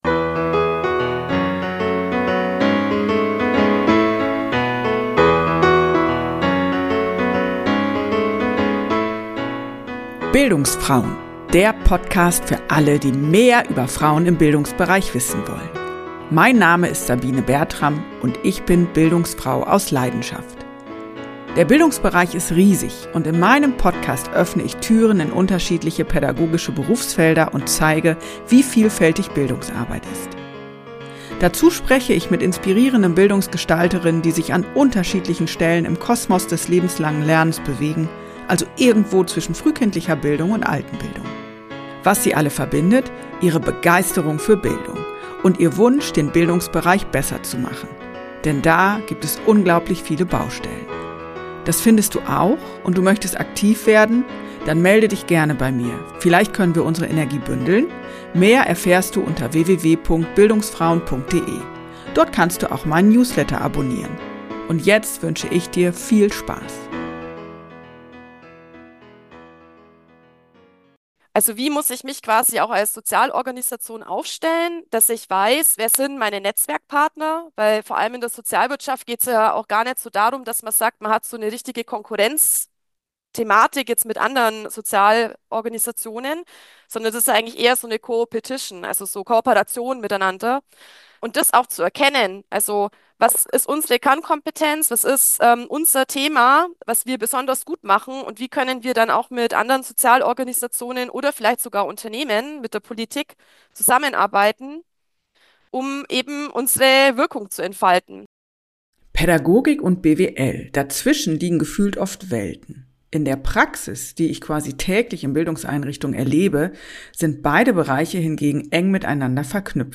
Freut euch also auf ein spannendes Gespräch!